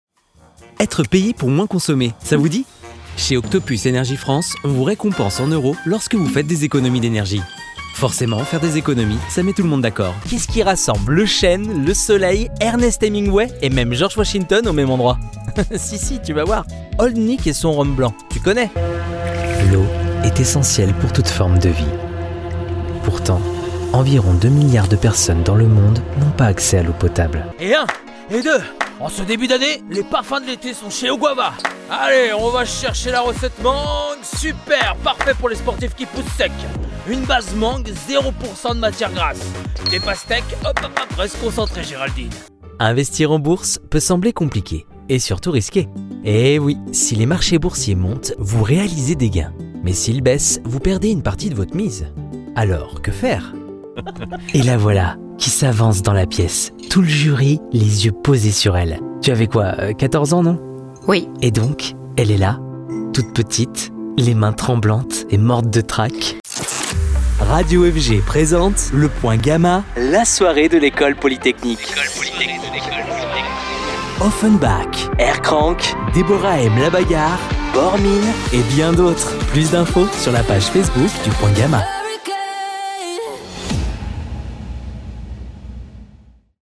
Démos Voix Off Personnage
- Ténor